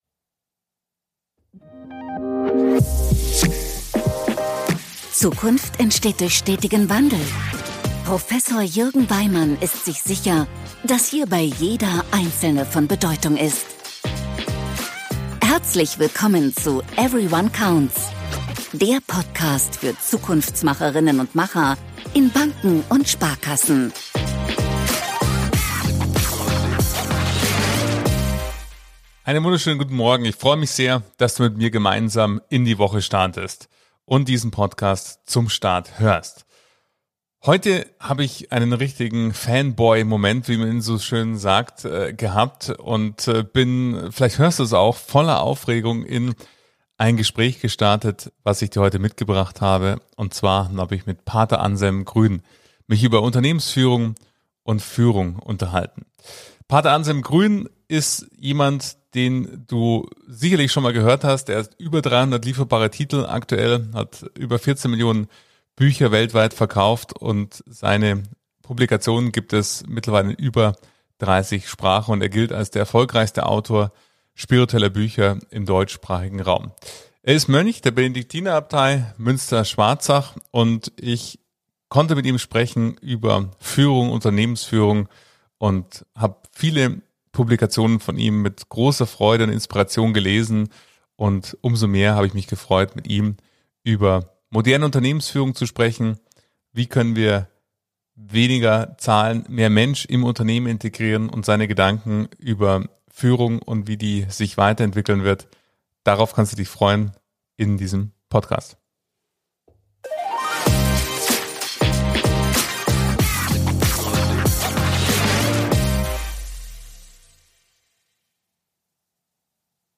Wie gelingt wirkungsvolle Führung? Gespräch mit Pater Anselm Grün ~ Everyone Counts - Transformation für Banken und Sparkassen Podcast